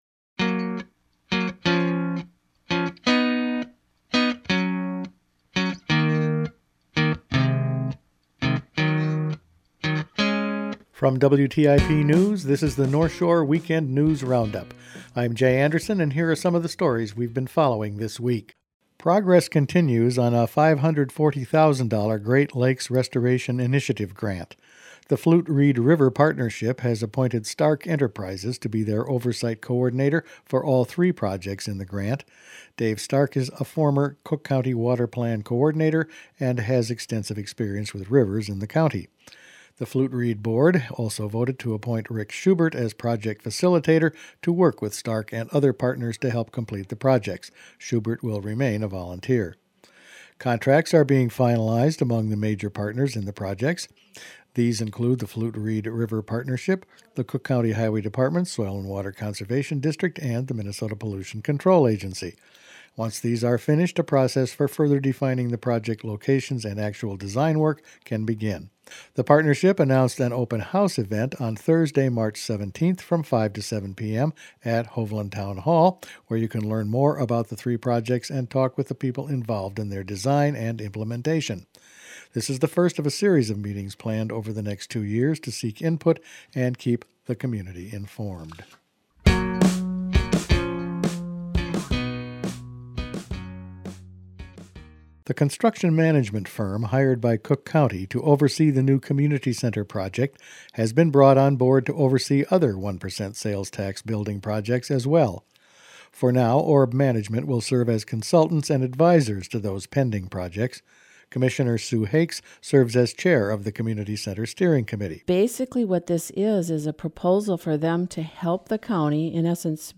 Each weekend WTIP news produces a round up of the news stories they’ve been following this week. Flute Reed River projects, help for the Great Lakes, another step forward on 1% sales tax projects and continued budget uncertainty for schools were in this week’s news.